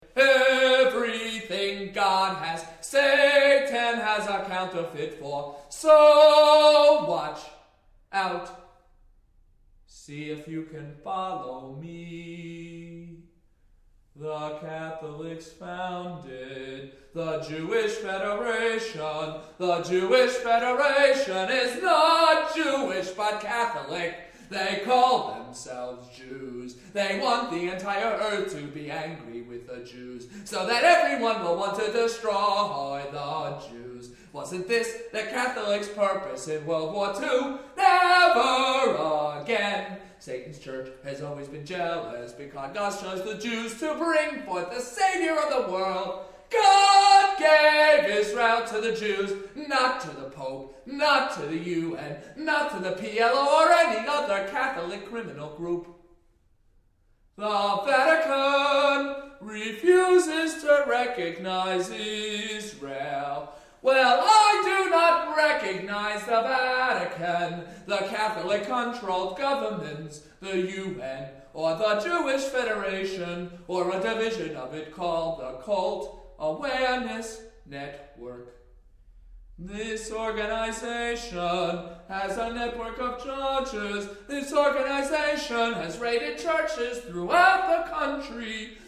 studio excerpt
a cappella voice